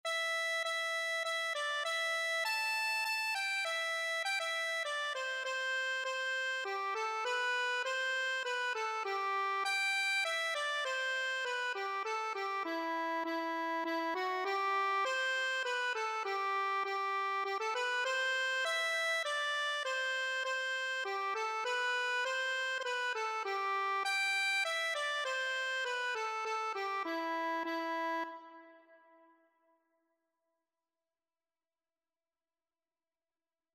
Free Sheet music for Accordion
3/4 (View more 3/4 Music)
G major (Sounding Pitch) (View more G major Music for Accordion )
Accordion  (View more Easy Accordion Music)
Traditional (View more Traditional Accordion Music)